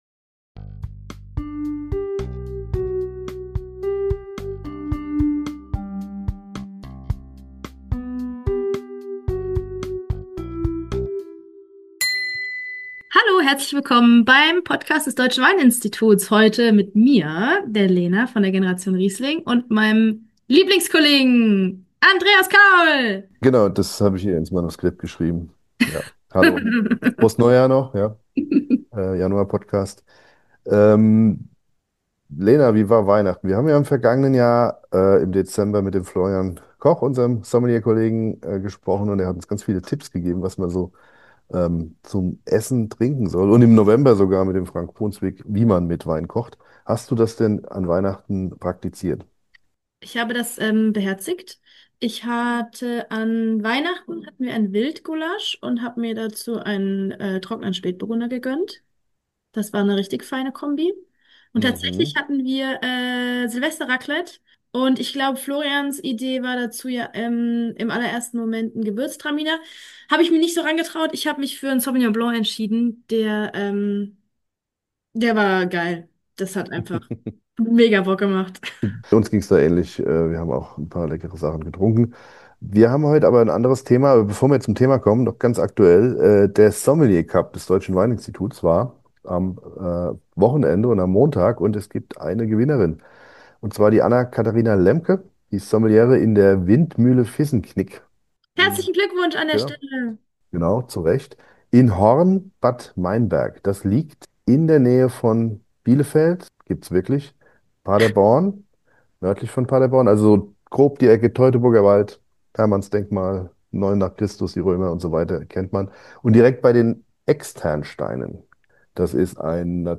Tiefe Dunkelheit, minus zehn Grad.
nimmt uns mit zur Eisweinlese des Jahrgangs 2025. Wir erfahren, warum es kein Problem ist, Helfer zu finden, wo man bei der Eisweinlese eine Feuerschale aufstellt, und dass beim Keltern von Eiswein kein Schneeballeffekt eintreten darf.